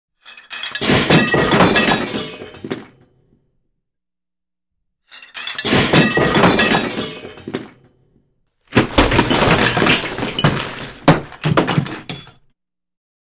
Звуки падения, грохота
Вариант 2 с кухонной утварью